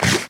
eat2.ogg